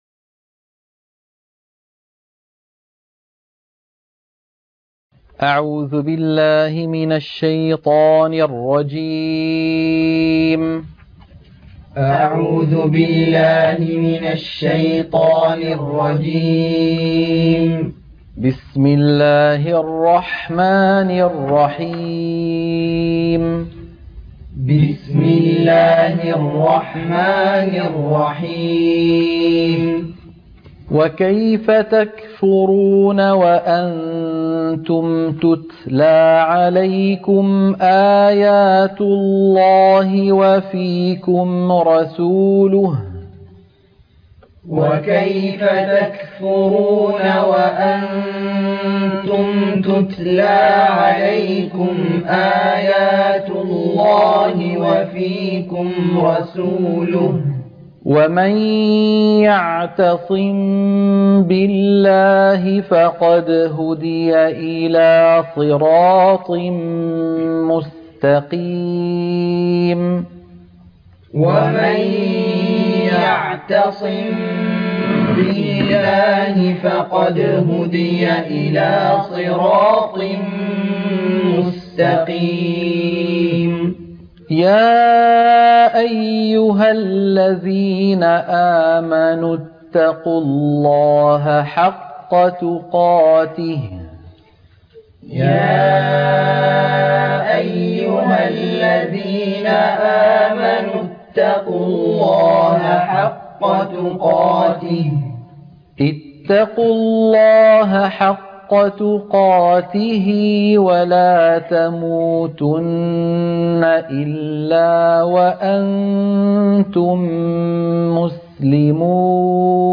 تلقين سورة آل عمران - الصفحة 63 التلاوة المنهجية - الشيخ أيمن سويد